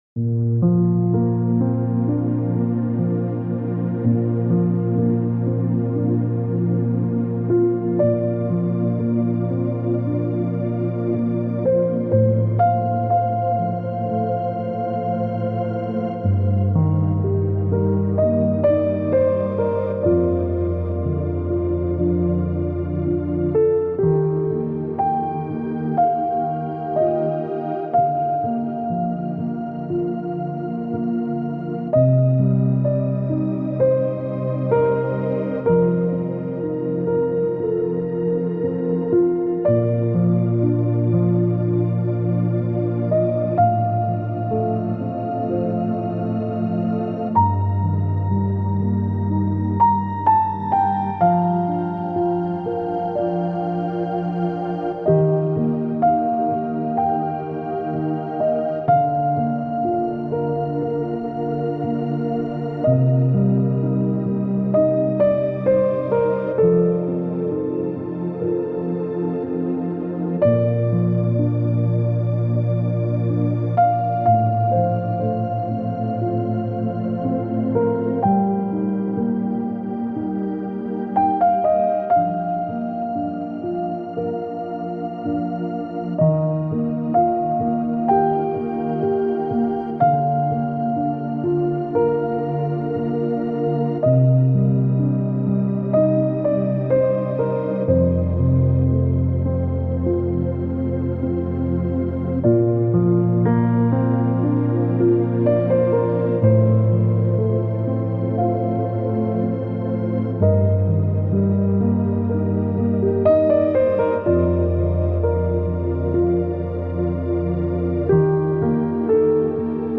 PARADOXE GEIST-BERUHIGUNG: Böen-Naturgewalt mit starken Winden